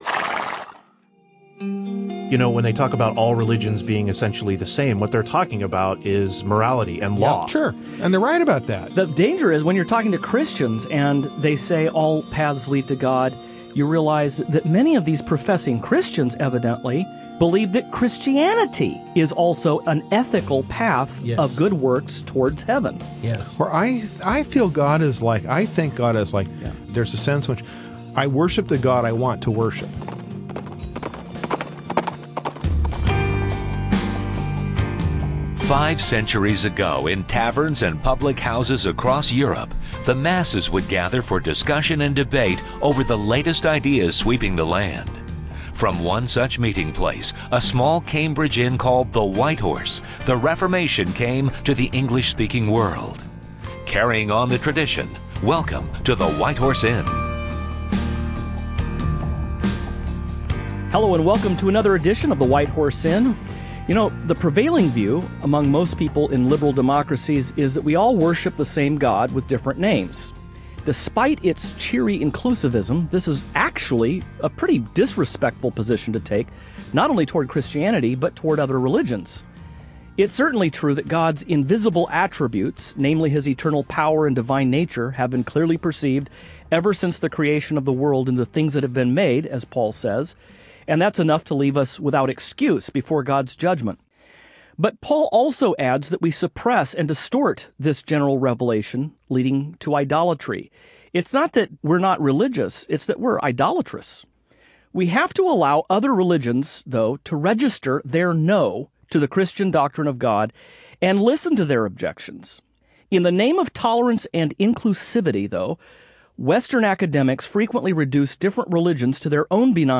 On this program the hosts continue their discussion of religious pluralism, and contrast the…